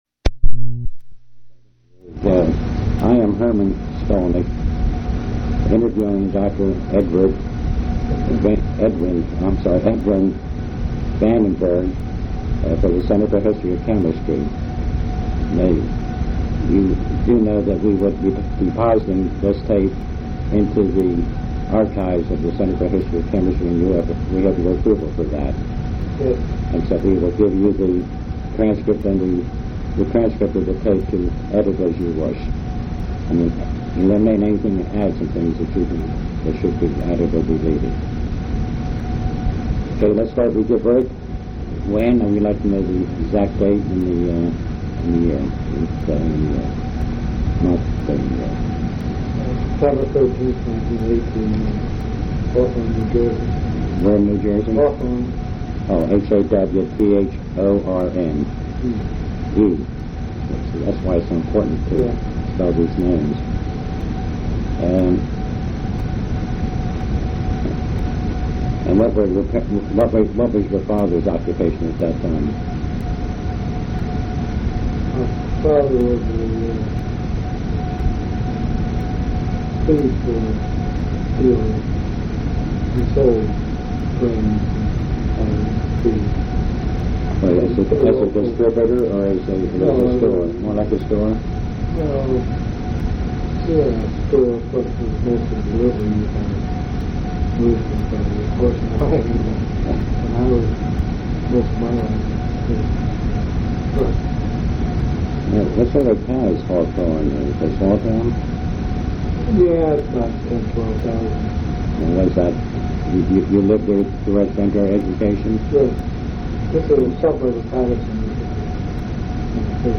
Place of interview Louisiana--New Orleans
Genre Oral histories